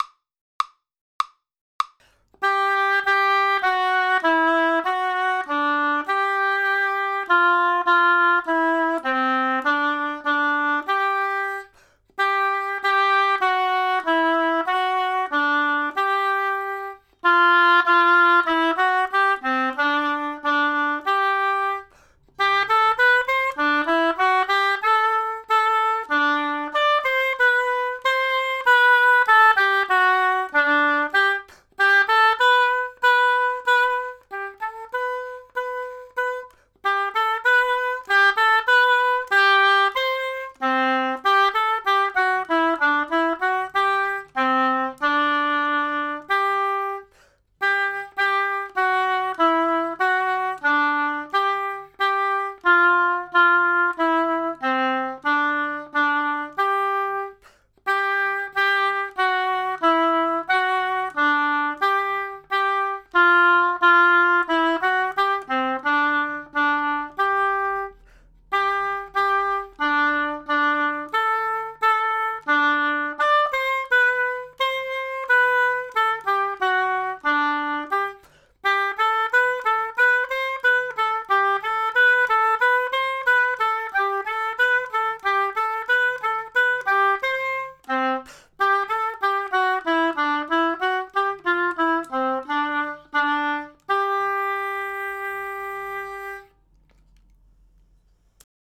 voix 2 au hautbois